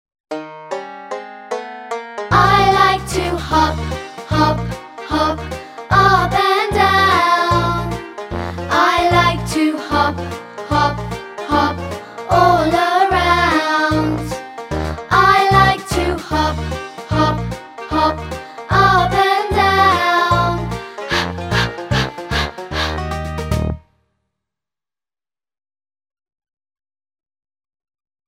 每个发音都配有一个用著名曲调填词的短歌和动作图示。